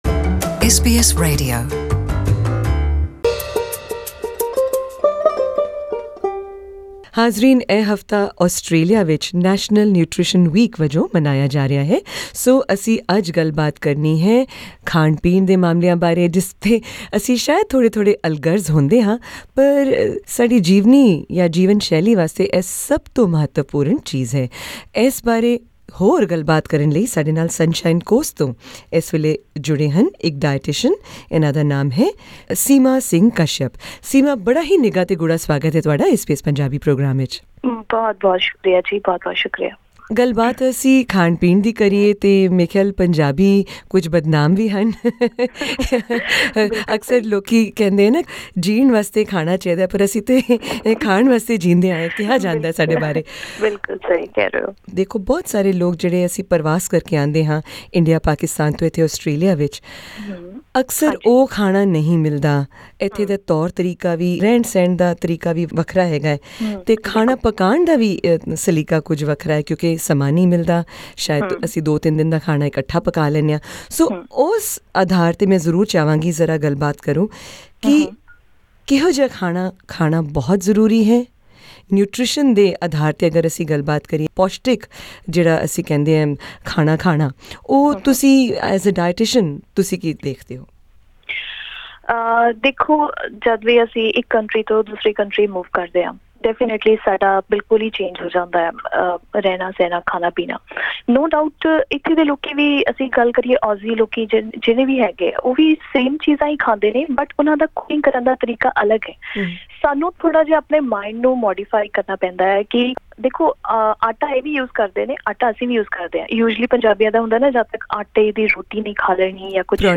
Hear answers to these and many more questions in our interview with a dietitian based on Gold Coast, Queensland.